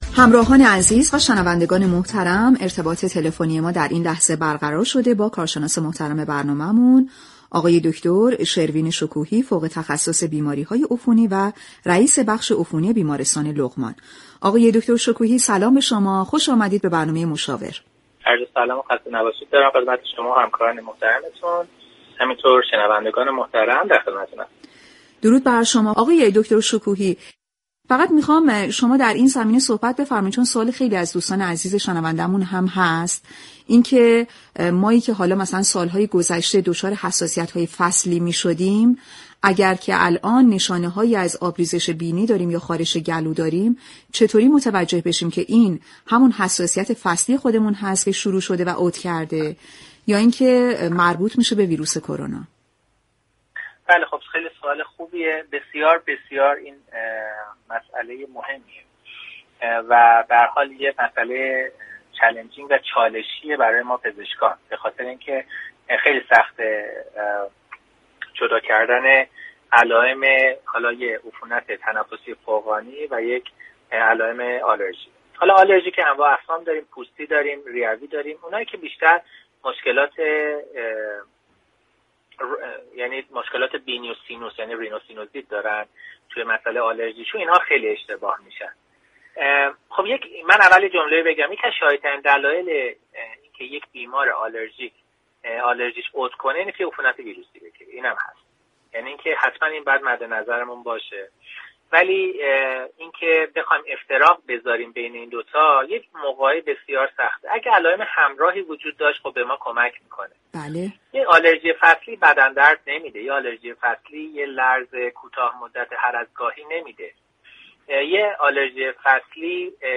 برنامه "مشاور" با محوریت آگاهی رسانی درباره كرونا هر روز ساعت 13:15 به مدت 15 دقیقه از شبكه رادیویی ورزش روی موج FM ردیف 92 مگاهرتز تقدیم شنوندگان می شود.